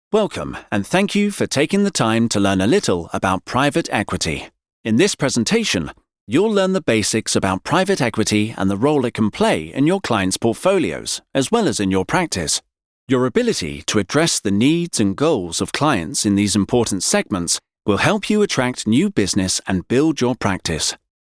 Anglais (Britannique)
Commerciale, Naturelle, Polyvalente, Chaude, Corporative
Guide audio
If you're looking for a smooth, versatile British male voice